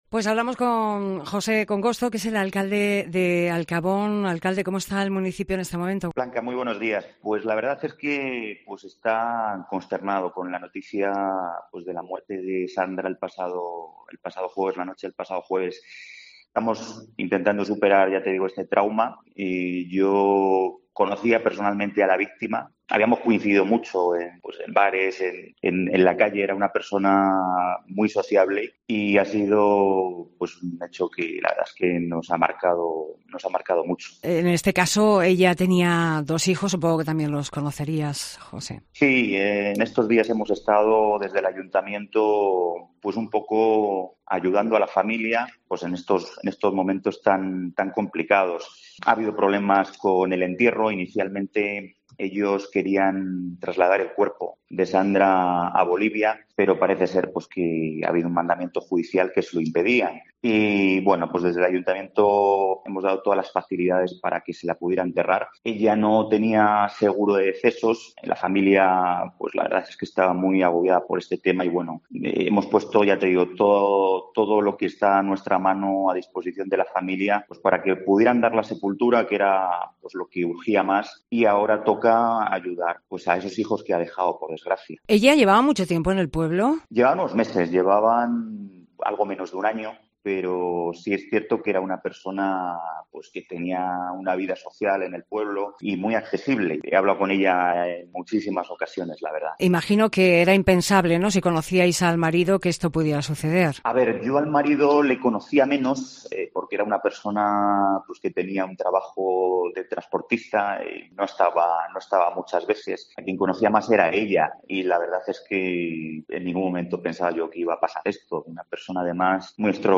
José Congosto, alcalde de Alcabón nos comentaba a los micrófonos de COPE